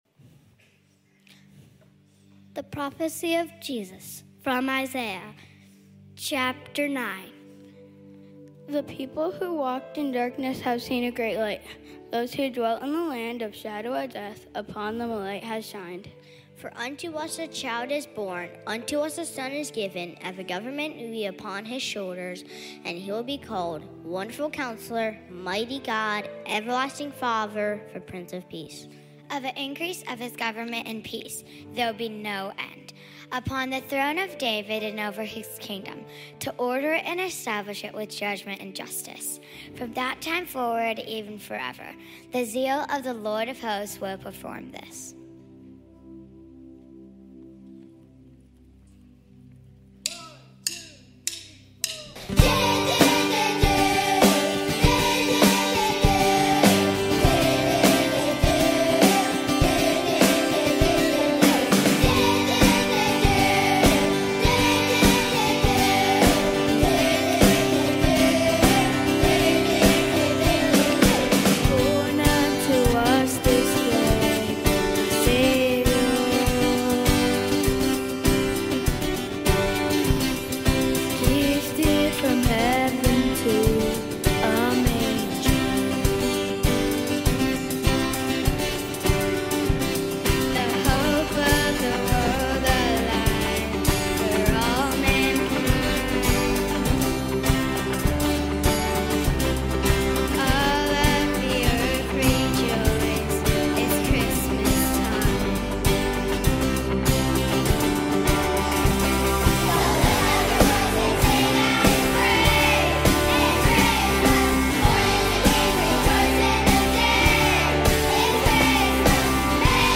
The full 2024 performance and devotional.